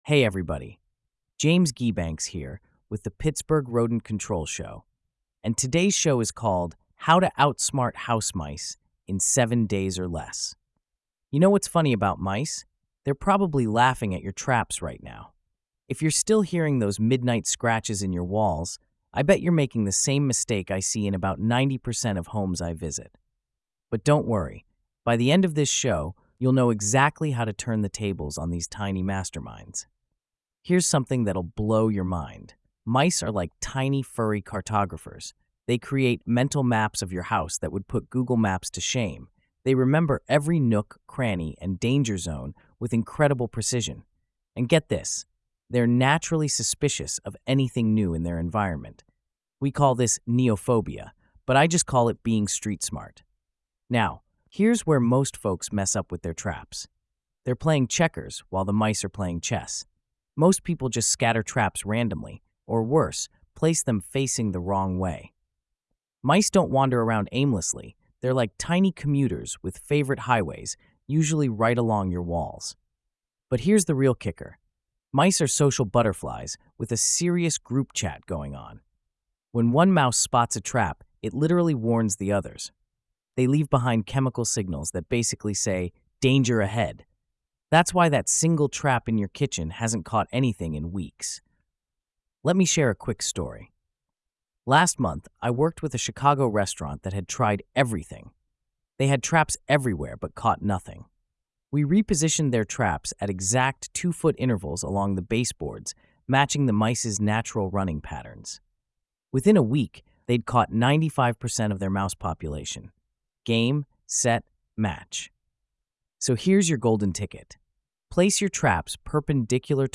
Join our team of local exterminators, urban wildlife experts, and longtime residents as we tackle the unique rodent challenges facing the City of Bridges.